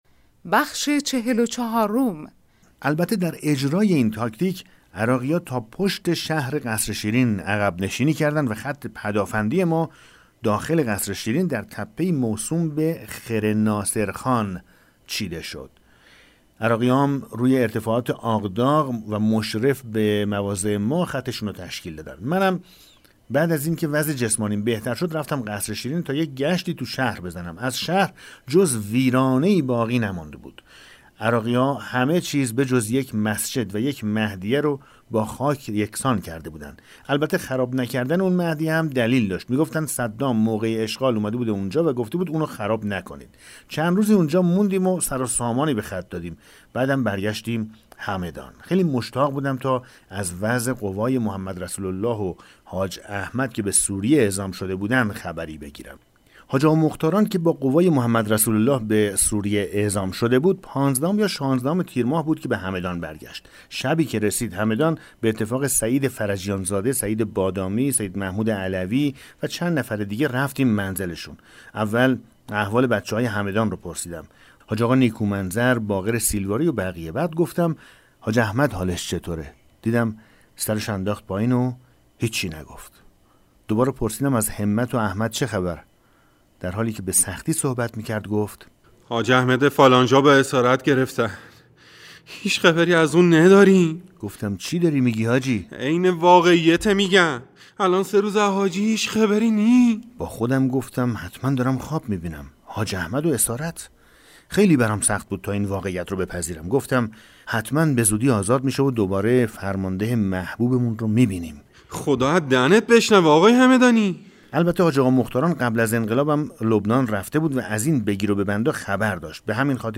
کتاب صوتی پیغام ماهی ها، سرگذشت جنگ‌های نامتقارن حاج حسین همدانی /قسمت 44